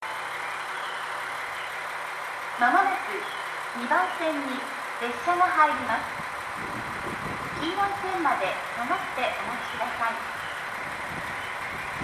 ２番線奥羽本線
接近放送普通　湯沢行き接近放送です。